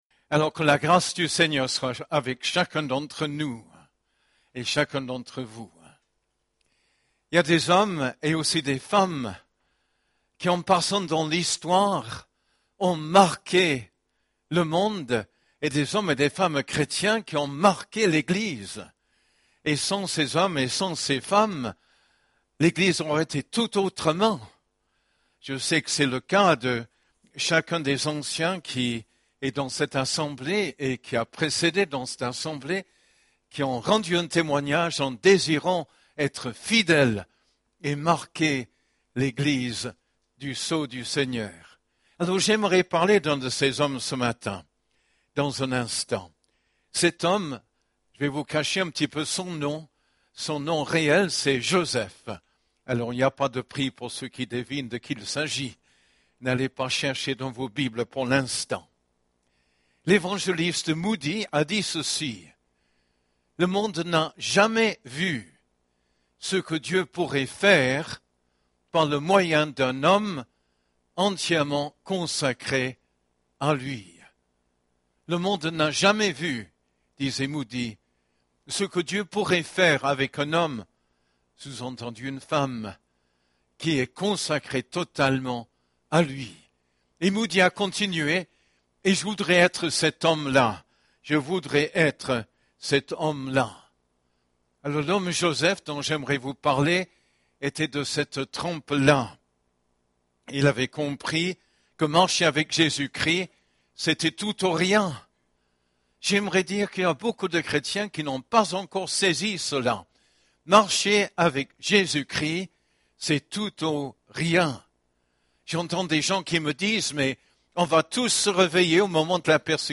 Culte du 15 janvier